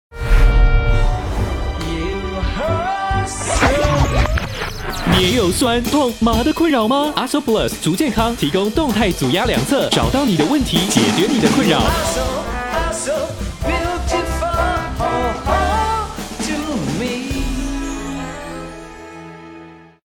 國語配音 男性配音員